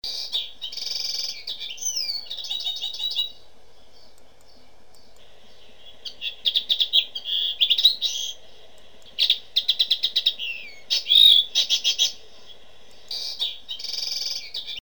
Pintarôxo-comum
Carduelis cannabina
Tem um canto suave e vibrante, com um gorjeio agudo em voo.
Pintarroxo3.mp3